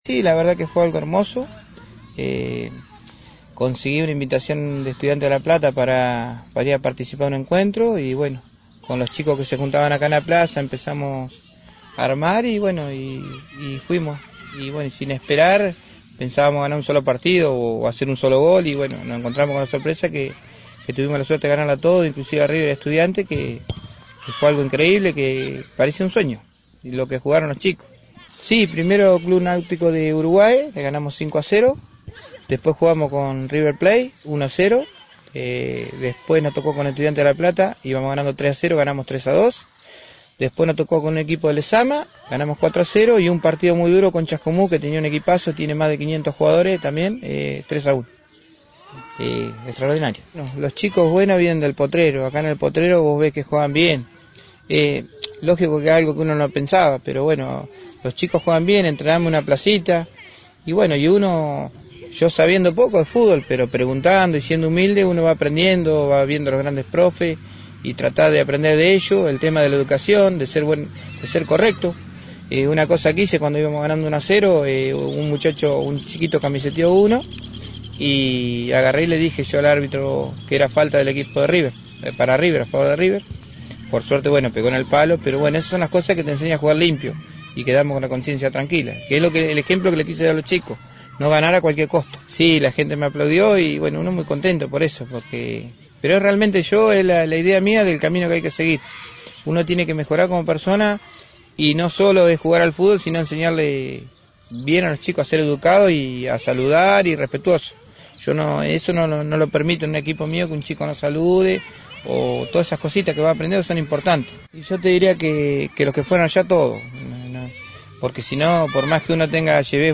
Con los chicos y chicas de fondo, jugando un picado, y padres y madres compartiendo un mate mientras miran